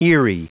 Prononciation du mot eerie en anglais (fichier audio)
Prononciation du mot : eerie